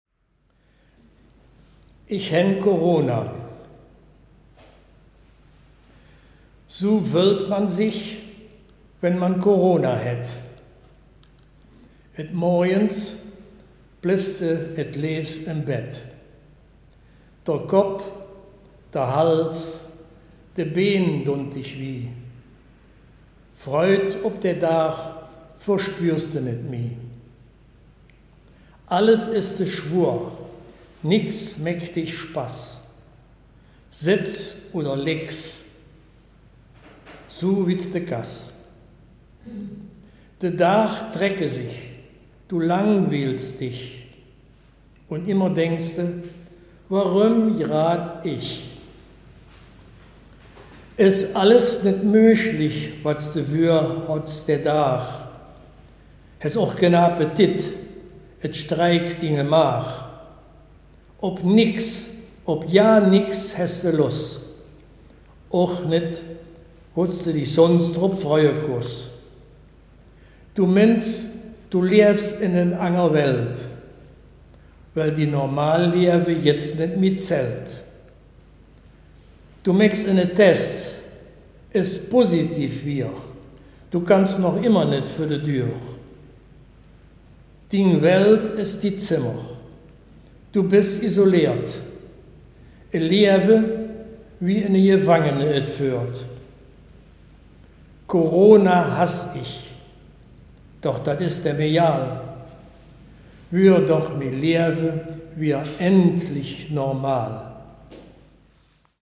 Baaler Riedelland - Erkelenzer Börde
Geschichte